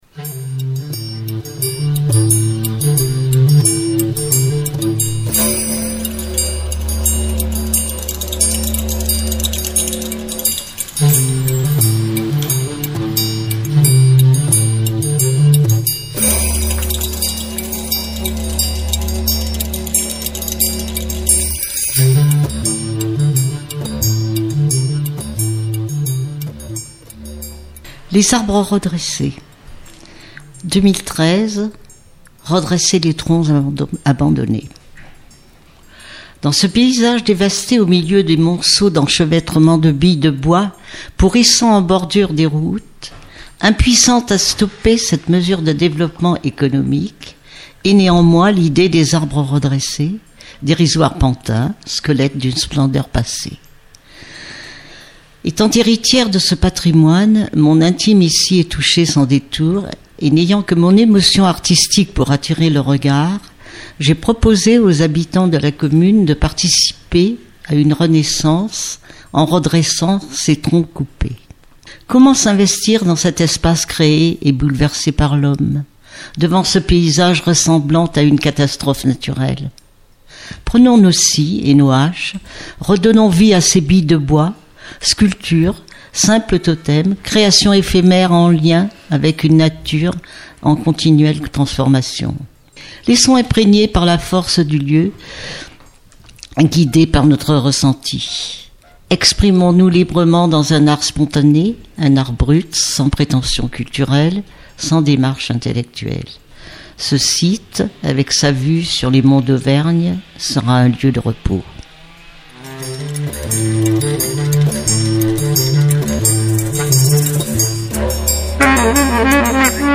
Une visite pleine de rencontres à la Gare en Commun de Felletin